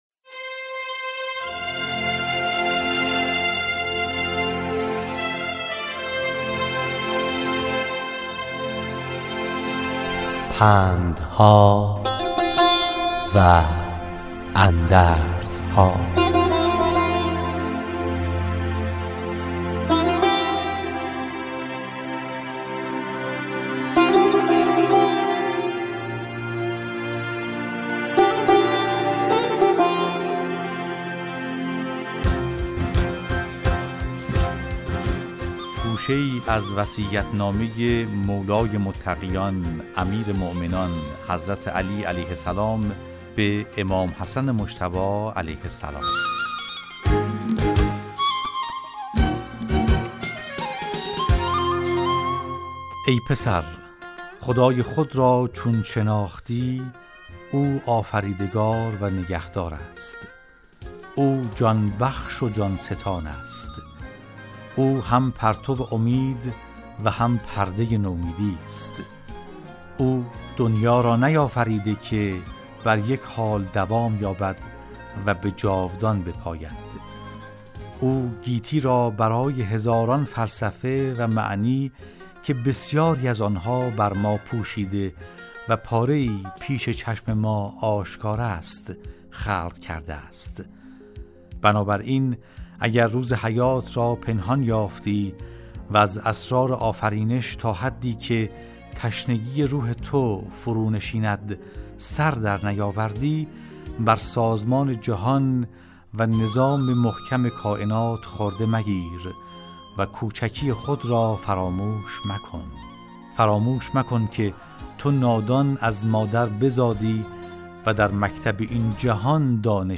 در برنامه " پندها و اندرزها"، راوی برای شنوندگان عزیز صدای خراسان، حکایت های پندآموزی را روایت می کند .